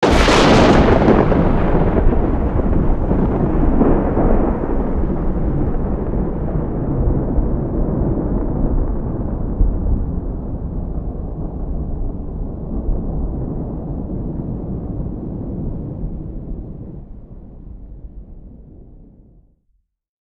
Divergent/thunder_13.ogg at 328d67128d658f2cc767bedffedb5bc97b7a0a30
thunder_13.ogg